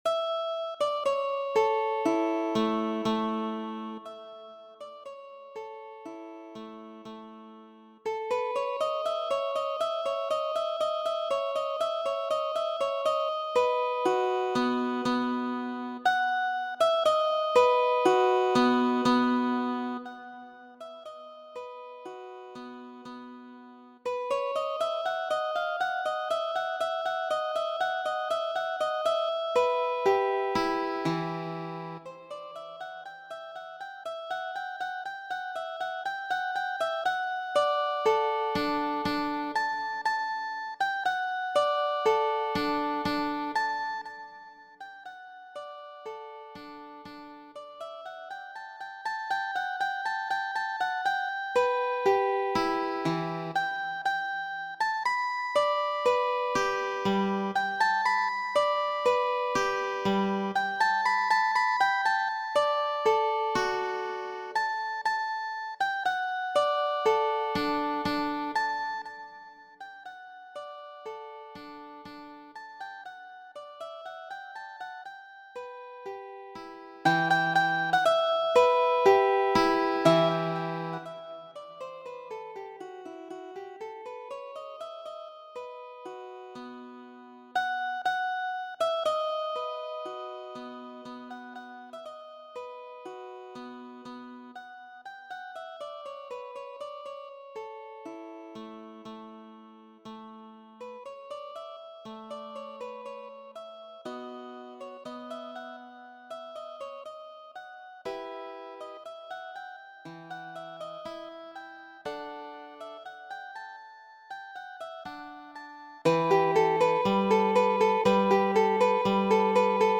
Viŭelo
Fantazion 10-an, kiu ŝajnigas la harpon je la maniero de Ludoviko , kiu estas vera studo harmonio de viŭelo.